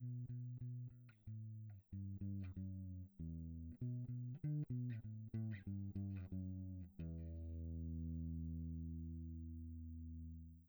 Before anyone start to wonder why I have uploaded 2 files with silence...
You will have to drive your card really hard (or chain 2 buses with +6 dB in Sonar) to hear something.
Both files are exported from Sonar, 16bit 44.1 kHz from the same just recorded n00b clip (with prior lowering the clip gate). One with and another without dithering.
WithDither2.wav